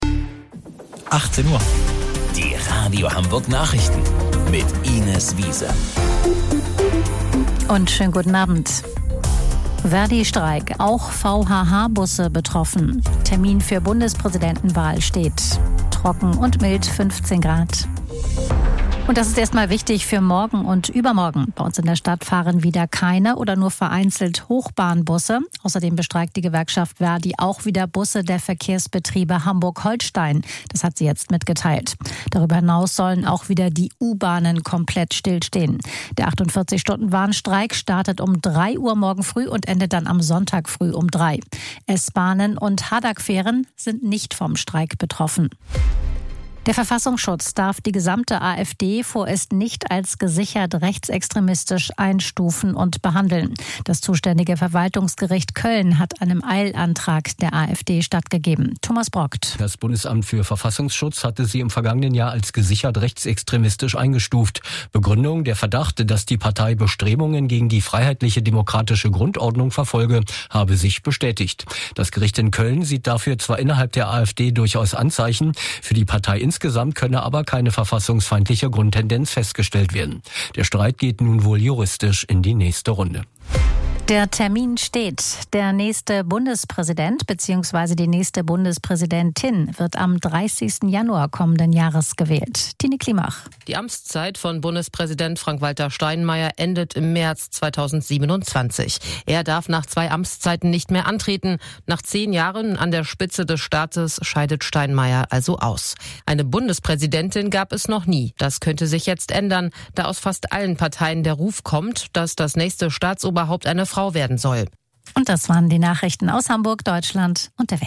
Radio Hamburg Nachrichten vom 26.02.2026 um 18 Uhr